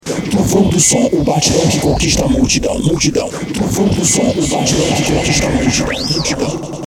Vinheta